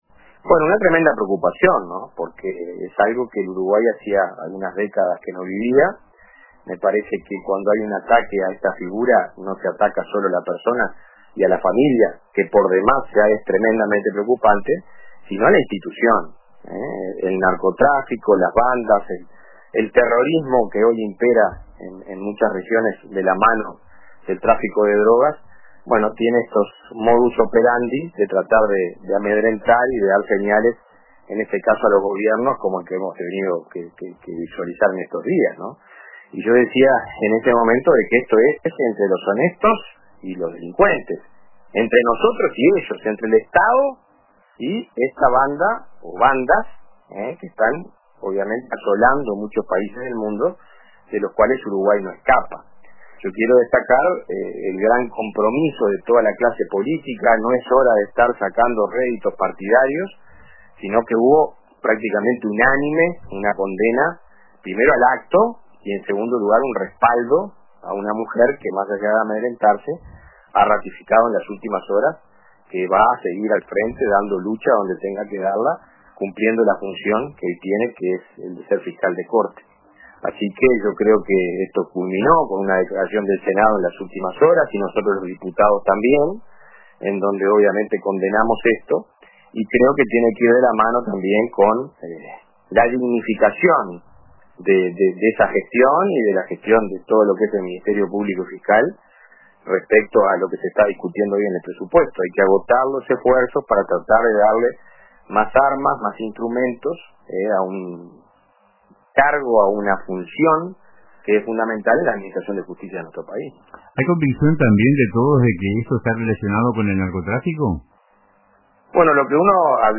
El diputado nacionalista por Maldonado, Dr. Federico Casaretto, participó del programa Radio con Todos de RBC, donde se refirió al atentado perpetrado en la madrugada del 28 de septiembre de 2025 contra la vivienda de la Fiscal de Corte, Mónica Ferrero.